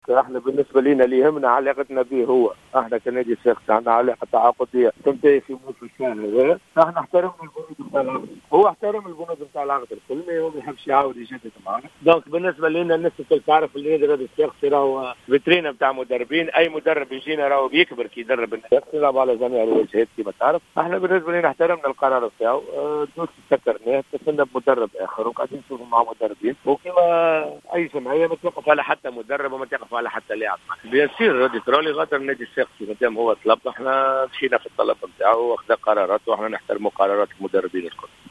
تصريح خاص براديو جوهرة افم